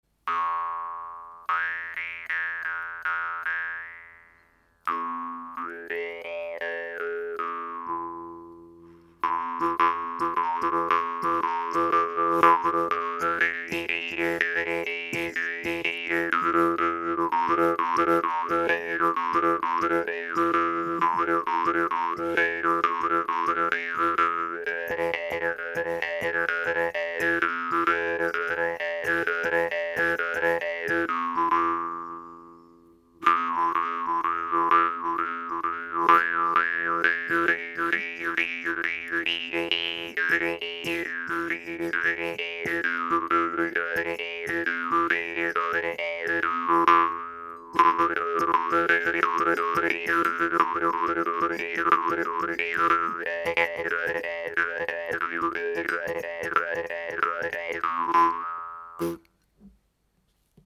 Le cadre forgé de cette guimbarde est très solide, très rassurant, la languette agréable à activer, le son peut être vraiment puissant.